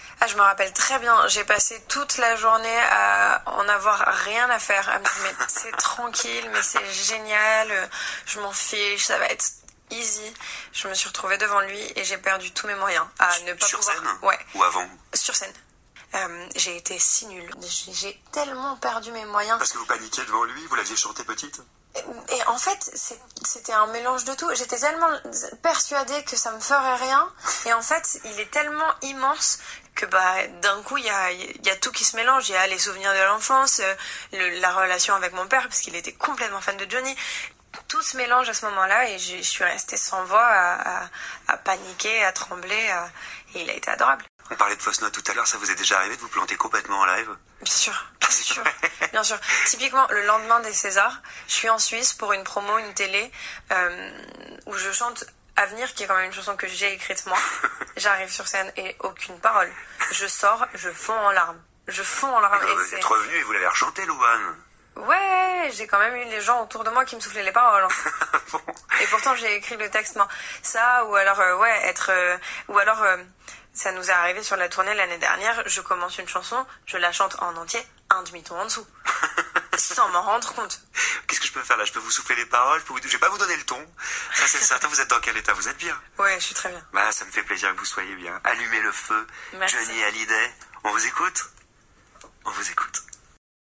Par hasard, j’ai écouté la fin d’une interview, puis le reste : une jeune chanteuse et actrice que vous connaissez si vous avez vu le film La Famille Bélier (dont une de mes étudiantes avait parlé ici).
La voix monte la première fois : c’est une question.